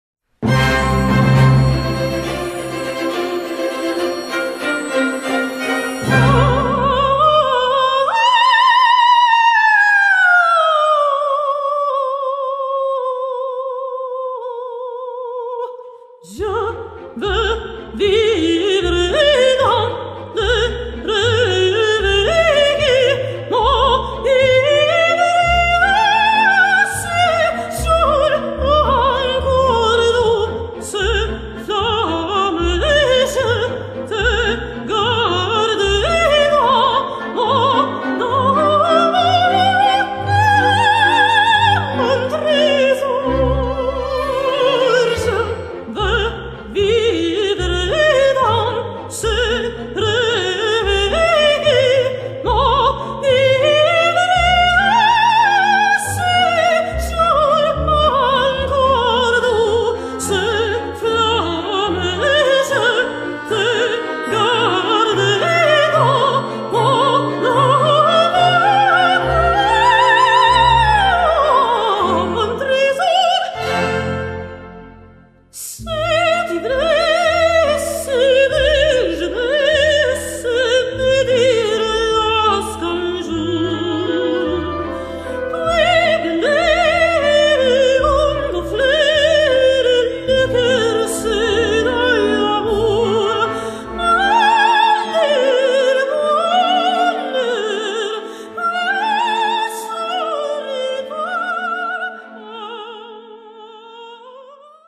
Voicing: Orchestra